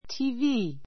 TV 小 A1 tíːvíː ティ ー ヴィ ー 名詞 テレビ(放送・受像機) ⦣ t ele v ision の略.